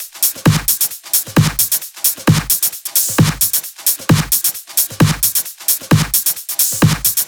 VFH3 132BPM Elemental Kit 6.wav